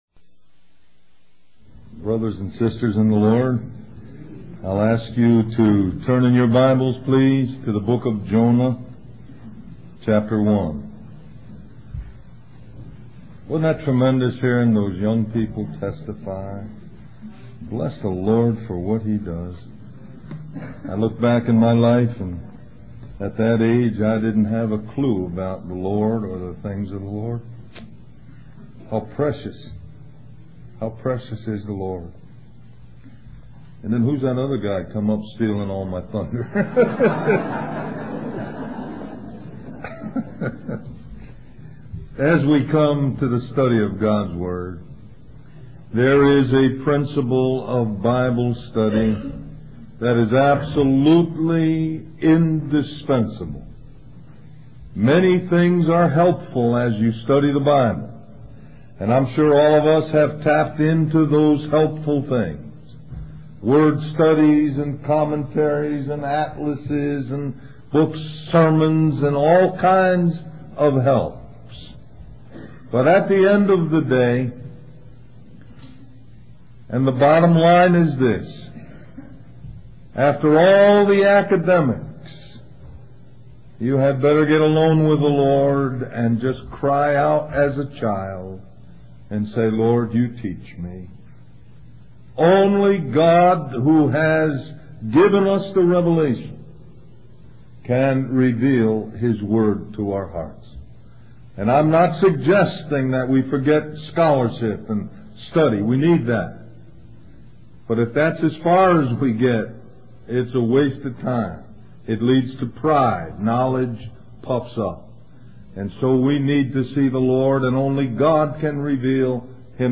A collection of Christ focused messages published by the Christian Testimony Ministry in Richmond, VA.
Del-Mar-Va Labor Day Retreat